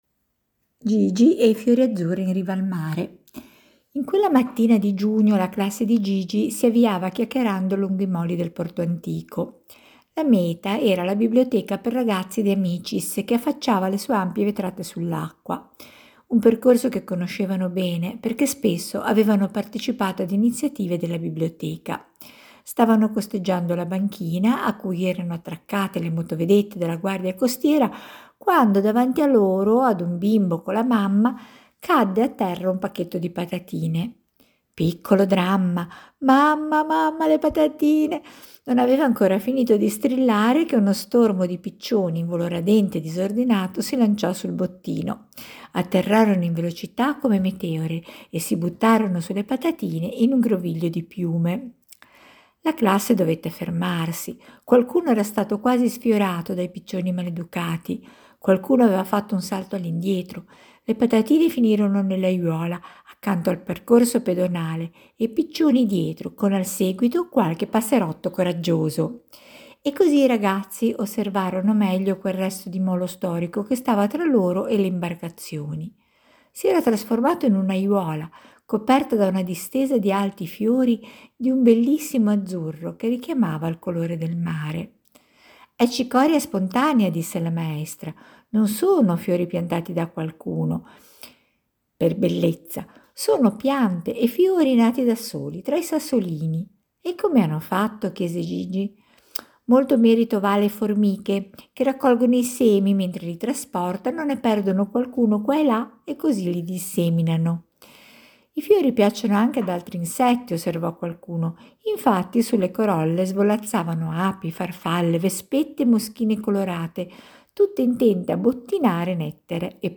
Brevi racconti scritti, letti e pubblicati da due volontarie con lo scopo di far conoscere ai bambini le piante che incontrano in città, spesso vicino a casa; alcune sono velenose e devono saperlo fin da piccoli, altre macchiano o pungono, ma tante altre curano e si mangiano!